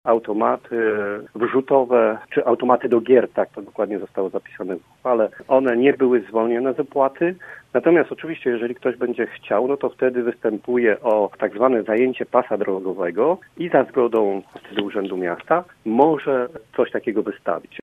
Dyskusja była długa, prezydent miasta zaproponował autopoprawkę – mówił w programie Sobota po 9 Piotr Barczak z PiS: